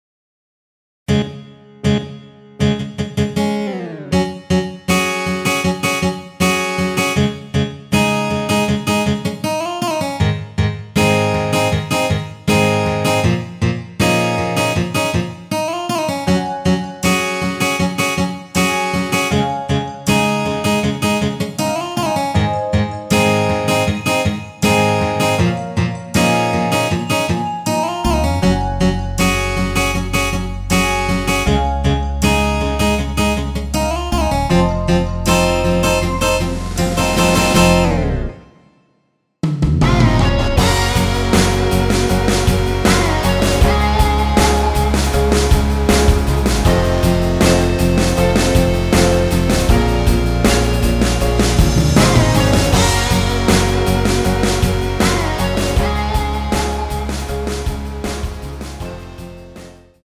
원키에서(-8)내린 대부분의 남성분이 부르실수 있는 키로 제작된 MR입니다.(미리듣기 참조)
Bbm
앞부분30초, 뒷부분30초씩 편집해서 올려 드리고 있습니다.
중간에 음이 끈어지고 다시 나오는 이유는